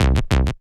TI98BASS2C-R.wav